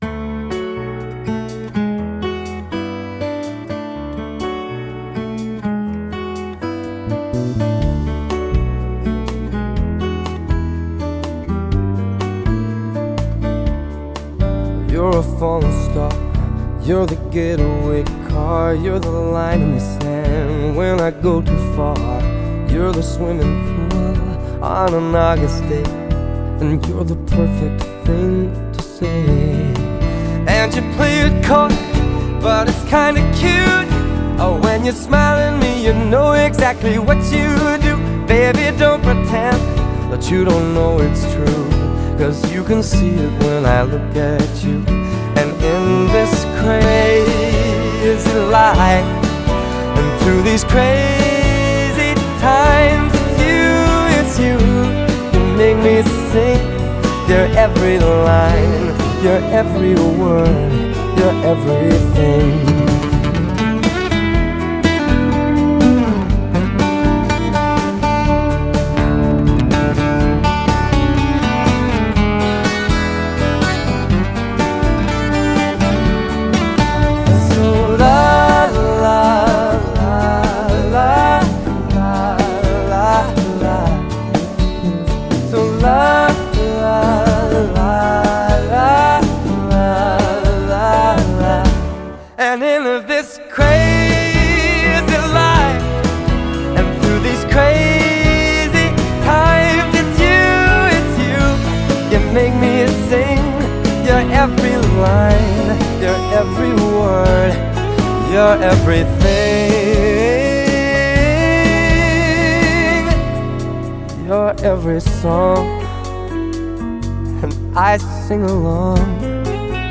BPM123
Audio QualityLine Out